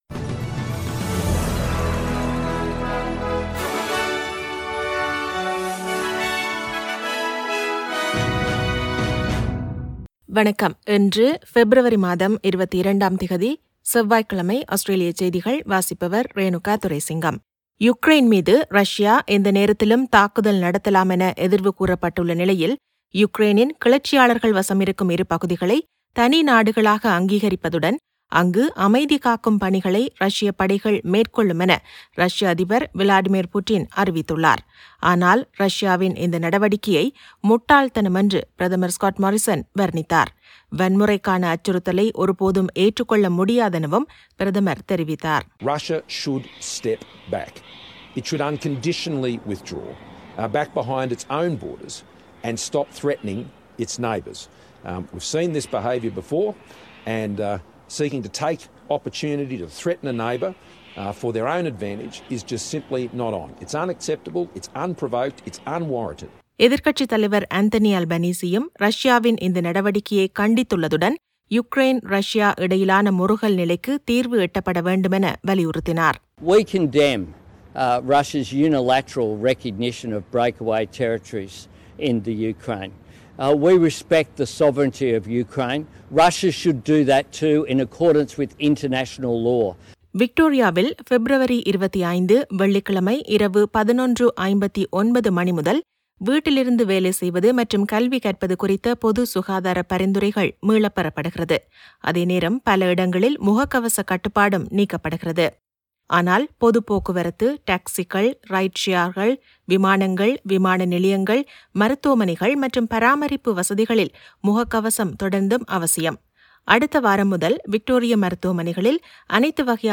Australian news bulletin for Tuesday 22 Feb 2022.